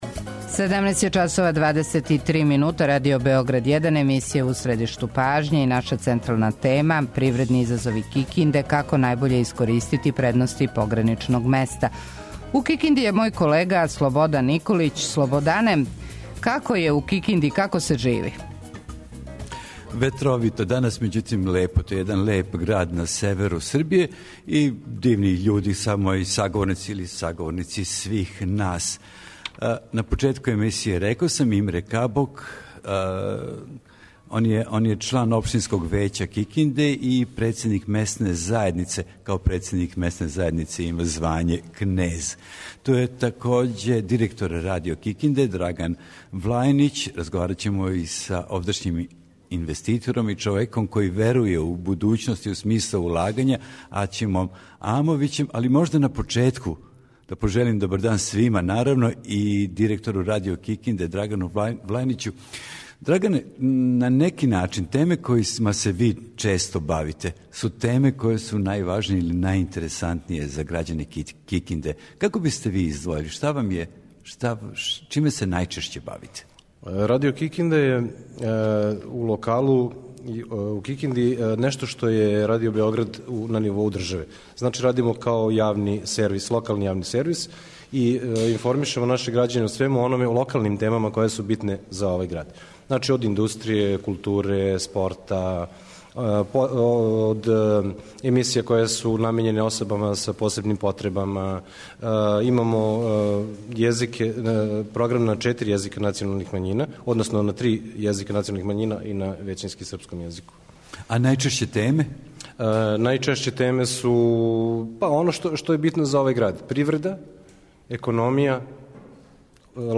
Привредни изазови Кикинде или како најбоље искористити предности пограничног места - о томе ћемо разговарати са 'великим' и 'малим' привредницима овог града са севера Баната.